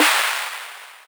DDK1 SNARE 1.wav